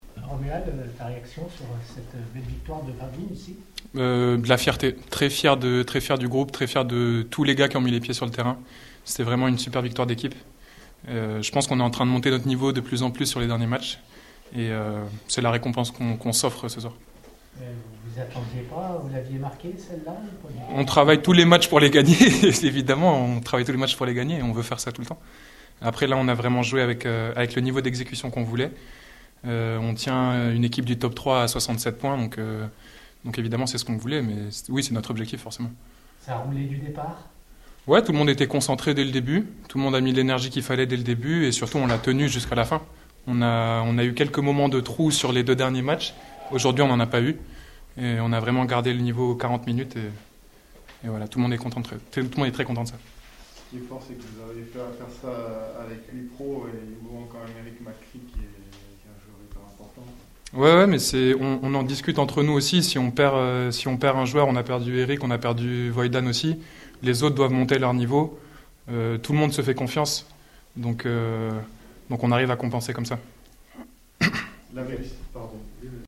LES Réactions